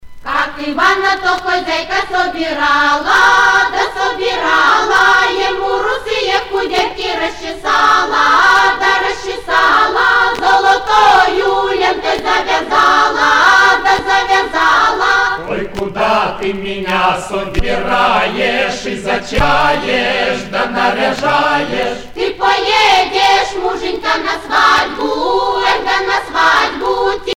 Région ou province Sibérie
Pièce musicale éditée